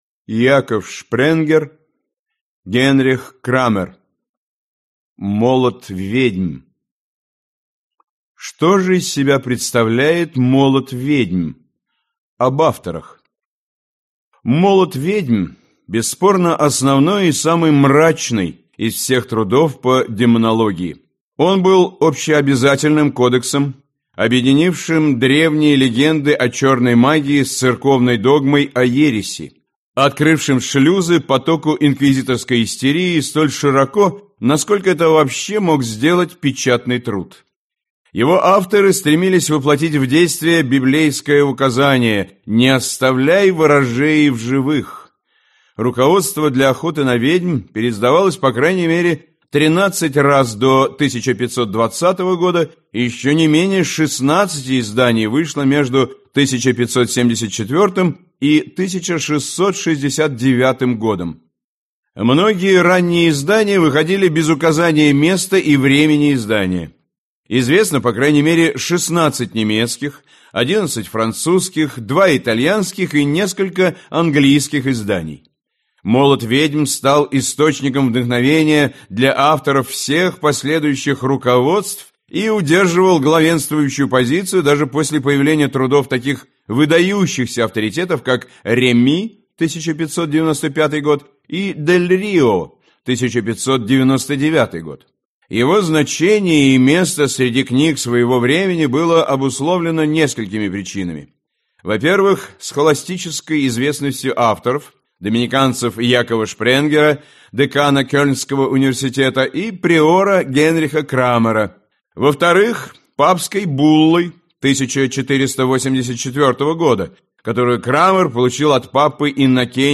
Аудиокнига Молот ведьм | Библиотека аудиокниг